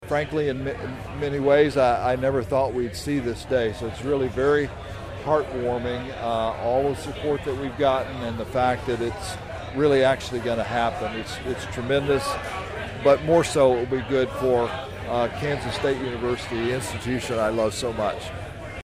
A groundbreaking ceremony for a new Agronomy Research and Innovation Center at Kansas State University had to be moved indoors Monday, due to rain, leading to a standing-room only crowd of guests inside the university’s Agronomy Education Center.